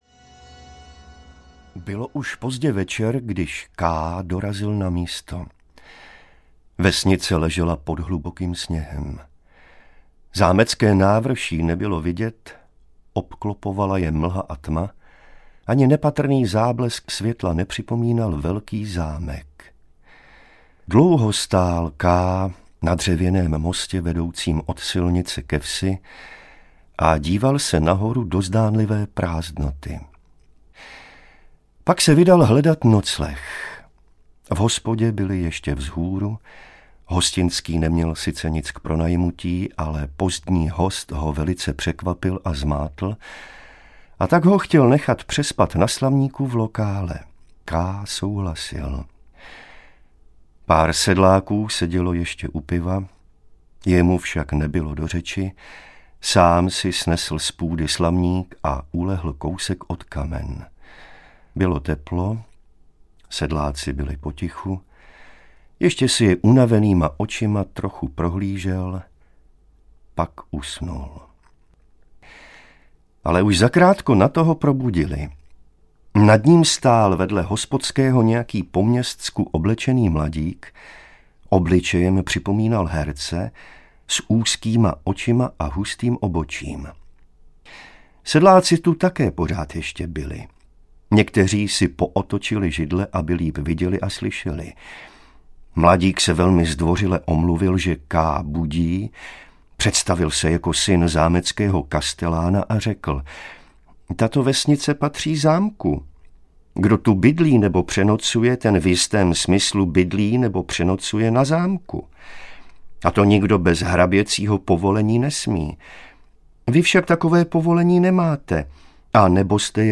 Zámek audiokniha
Ukázka z knihy